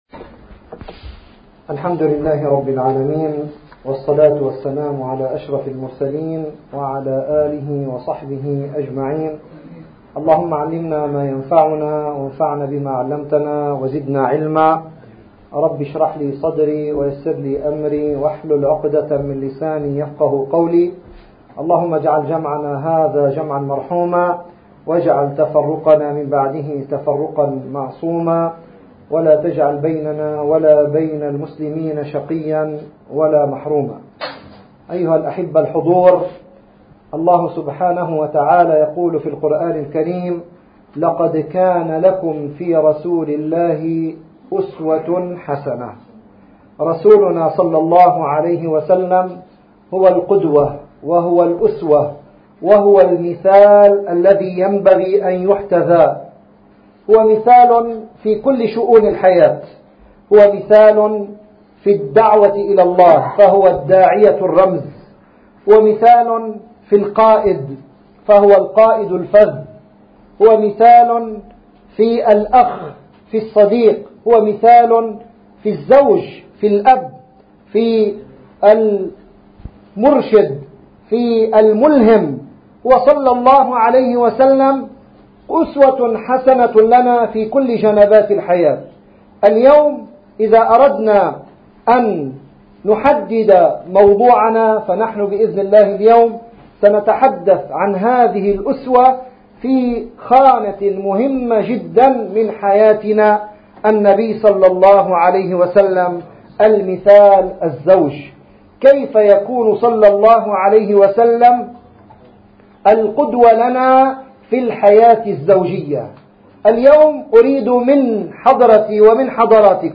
الحبيب الزوج ﷺ – محاضرة